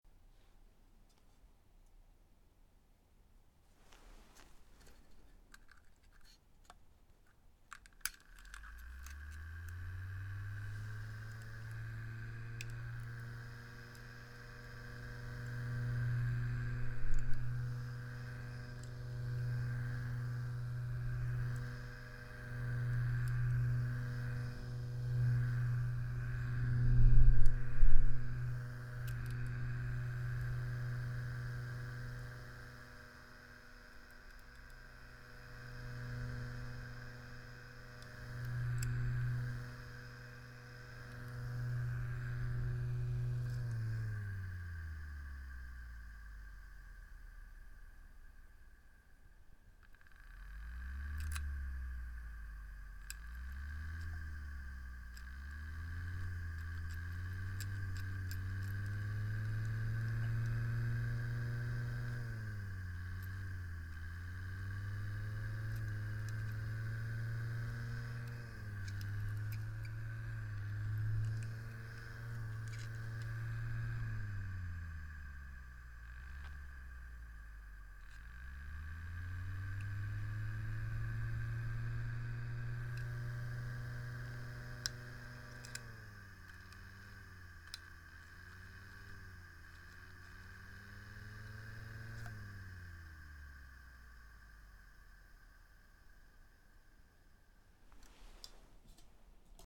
Fan-Noise-05.mp3